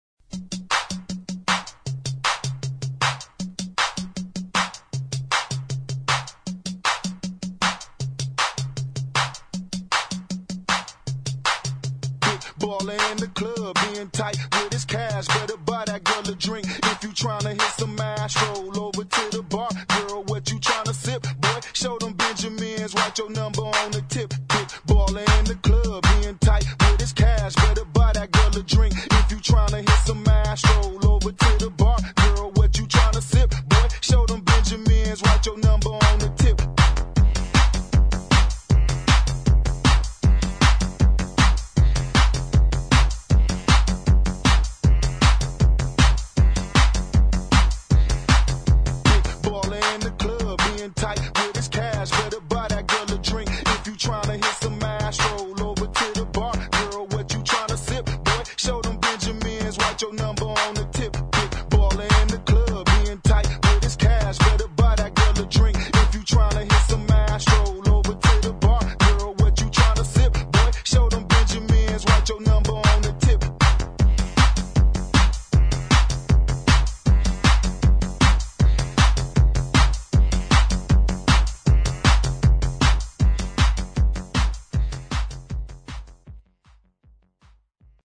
[ GHETTO | ELECTRO ]
エレクトロでファンキーなゲットー・テック・ベース！